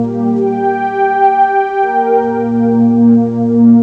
cch_synth_loop_rolled_125_Gm.wav